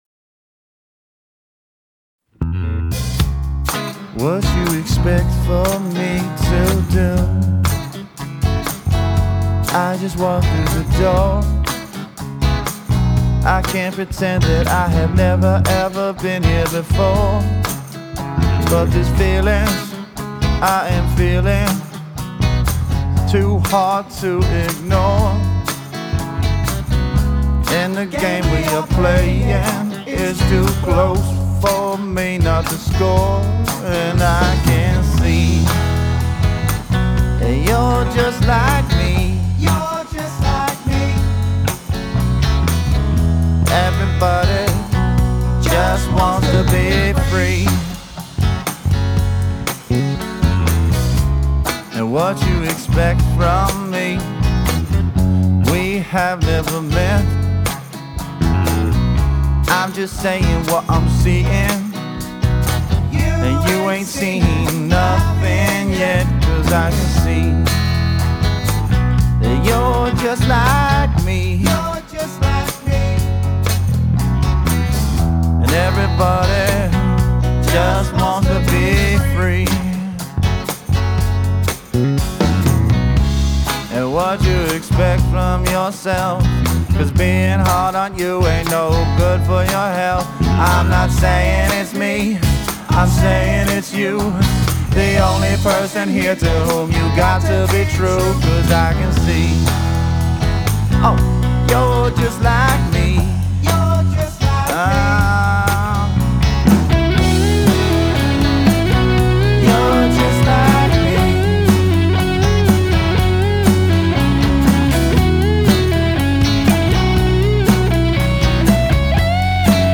Genre: Rock, Funk Rock, Blues